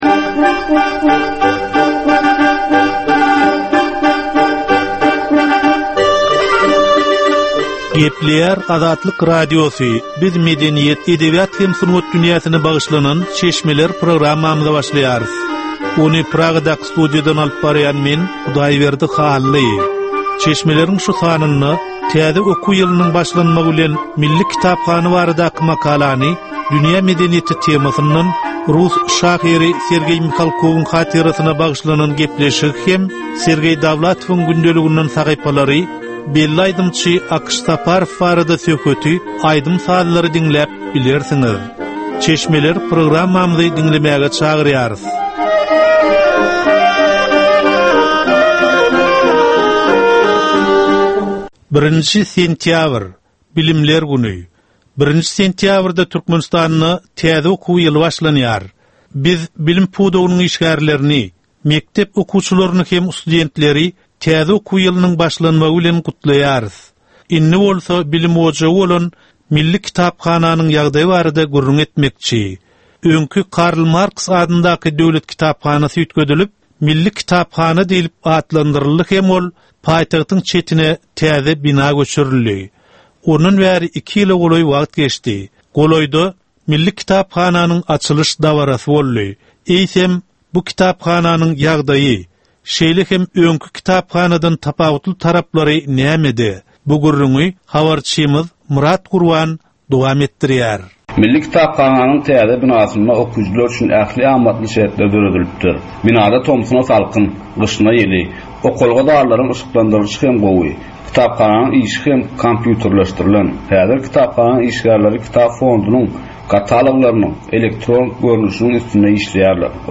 Edebi, medeni we taryhy temalardan 25 minutlyk ýörite geplesik.